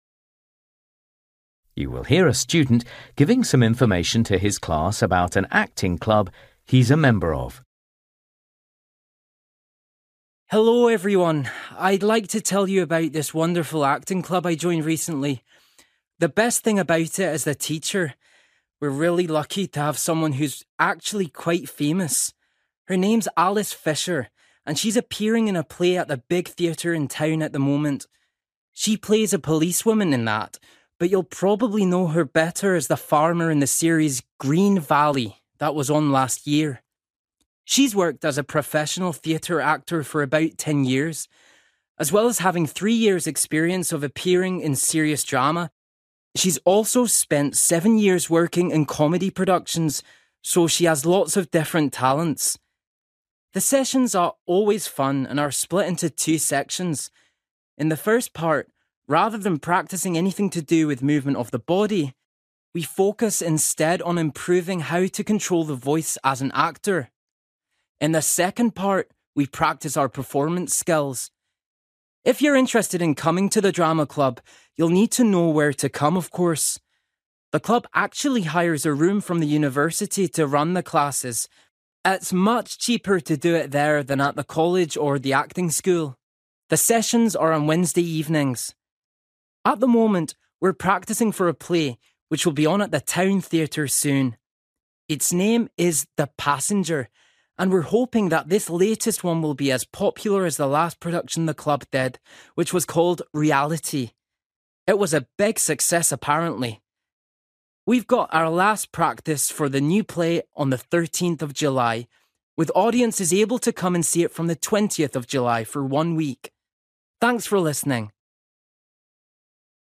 You will hear a student giving some information to his class about an acting club he’s a member of.